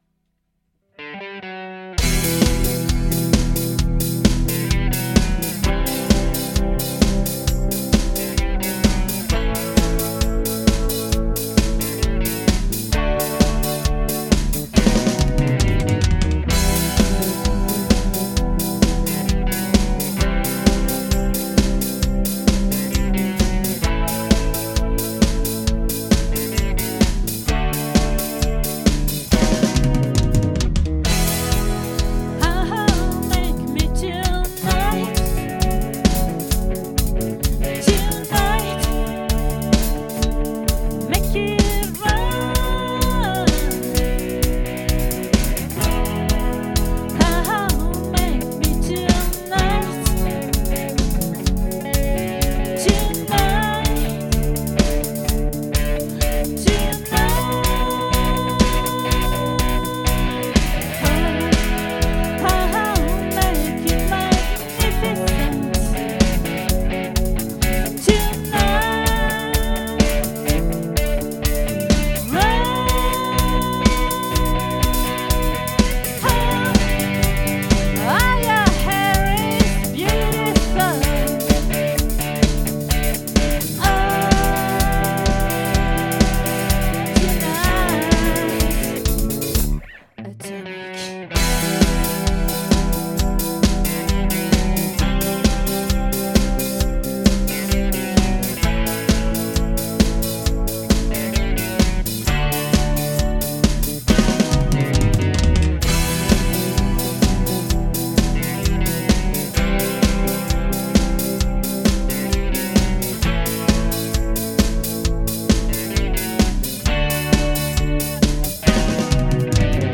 🏠 Accueil Repetitions Records_2022_06_29